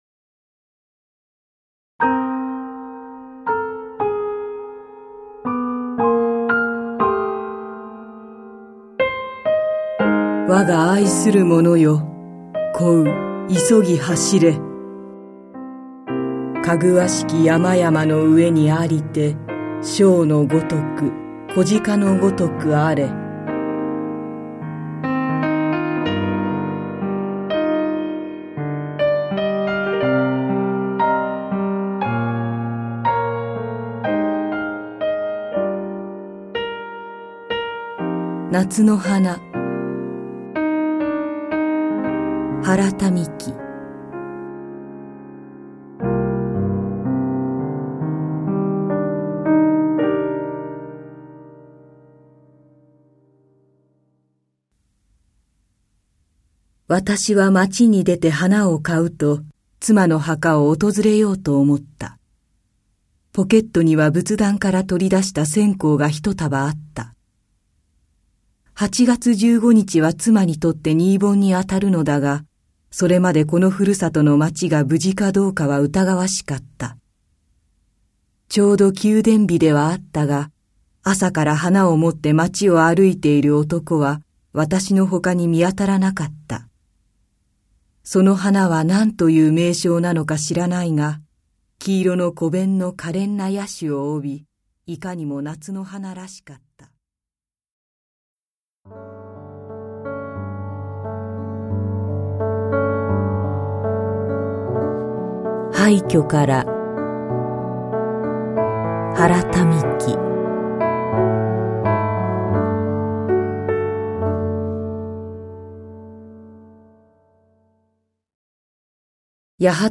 [オーディオブックCD] 夏の花